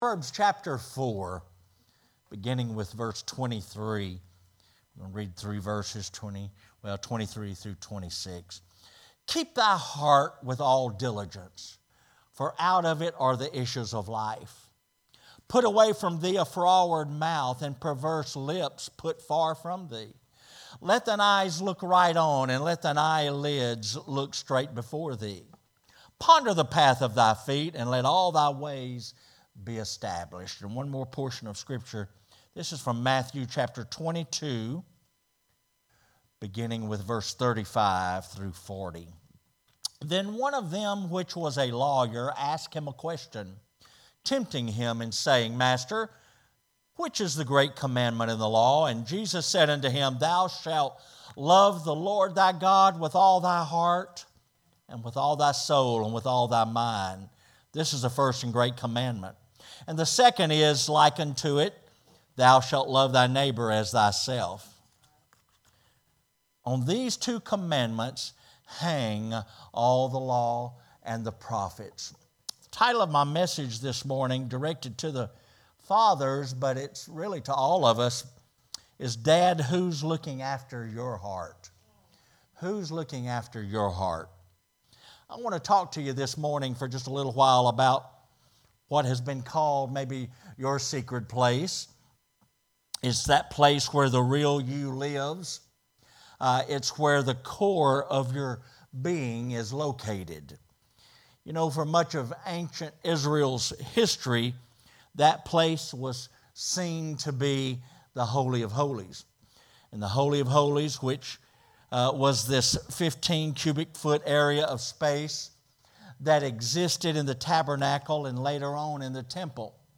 Sermons | New Life Ministries